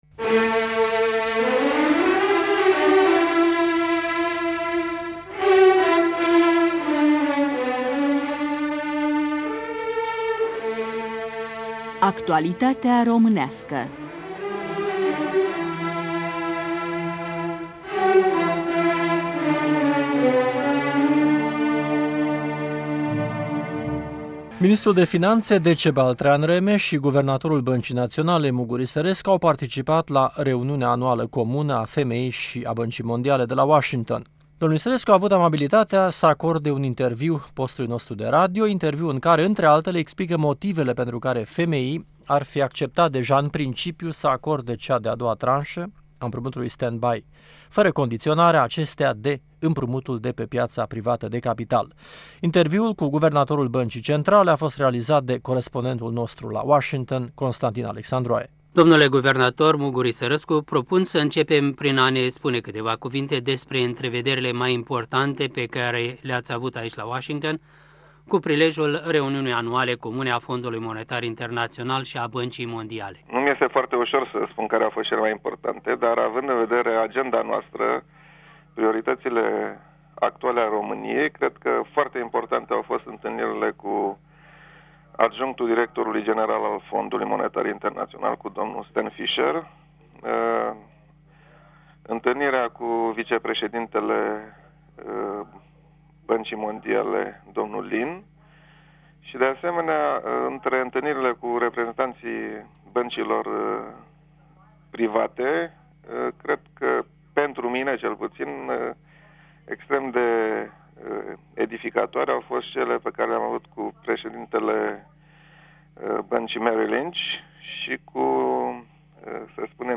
Interviu cu Mugur Isărescu la Washington